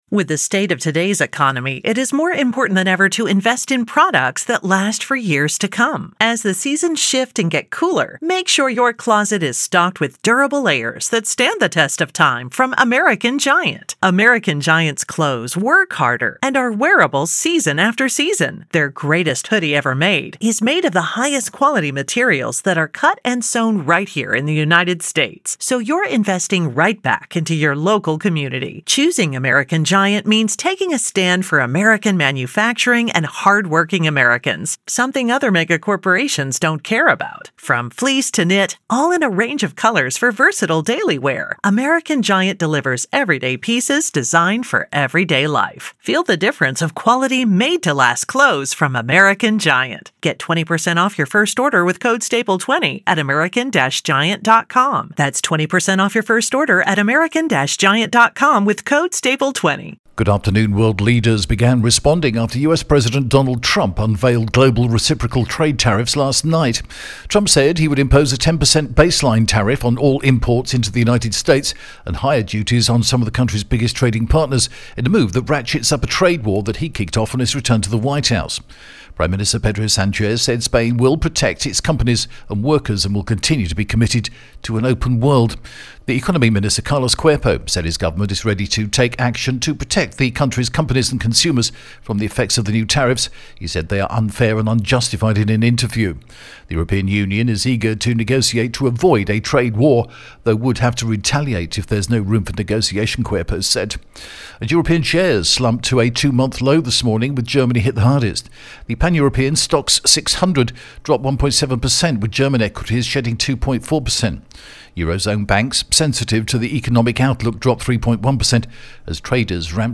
The latest Spanish news headlines in English: April 3rd 2025